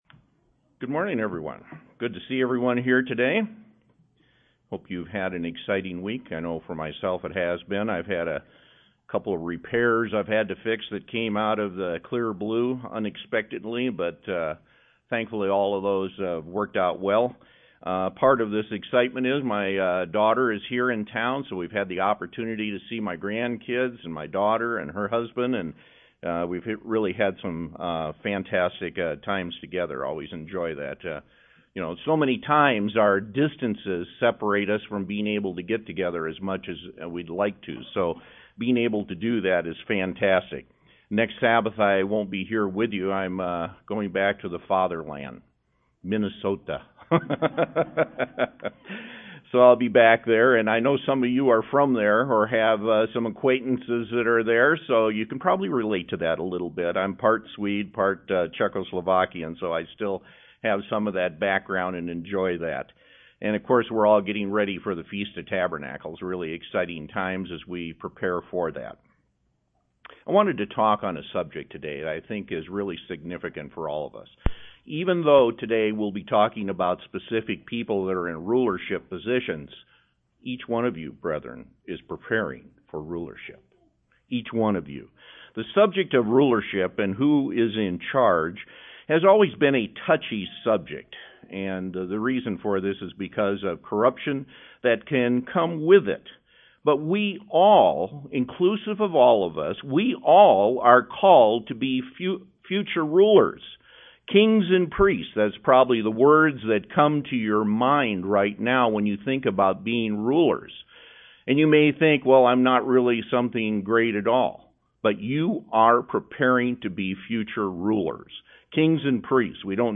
This sermon goes through the story of Samuel and shows that these characteristics were in people like Hannah, Eli, and Samuel.
Given in Denver, CO